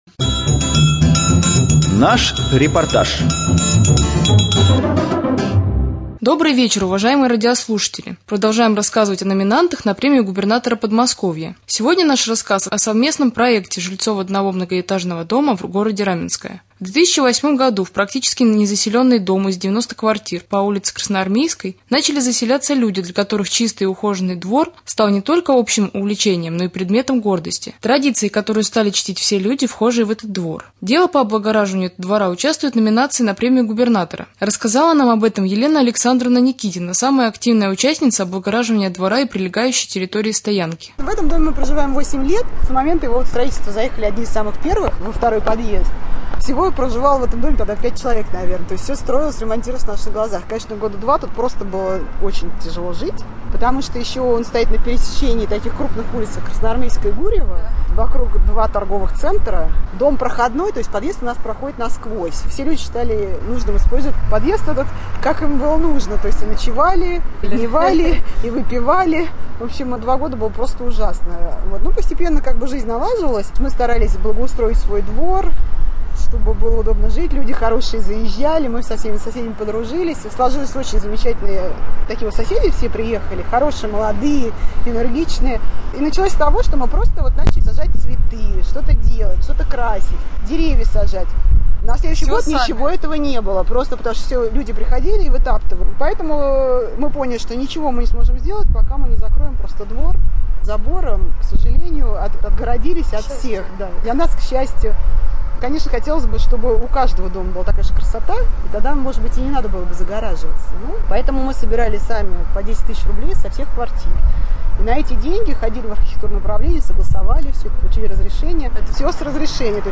4.Рубрика «Специальный репортаж».